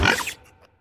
Cri de Gourmelet dans Pokémon HOME.